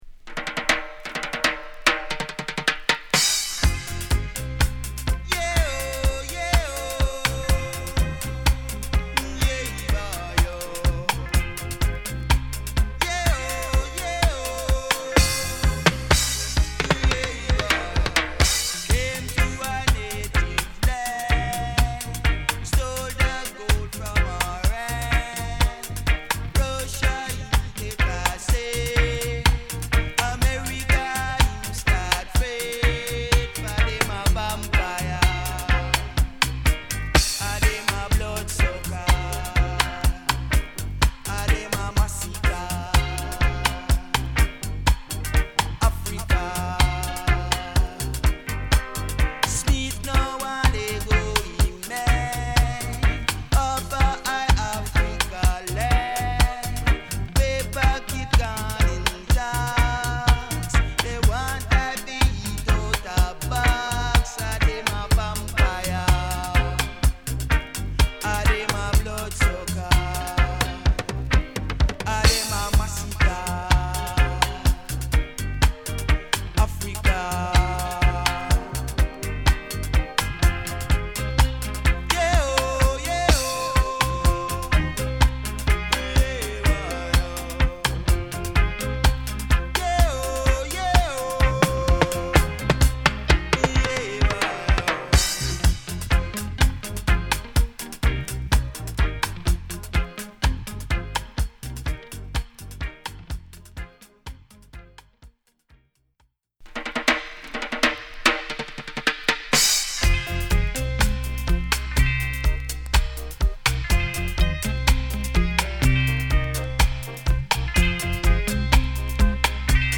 」まで、カッコ良いステッパー〜ルーツ〜ダブを収録！